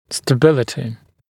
[stə’bɪlətɪ][стэ’билэти]стабильность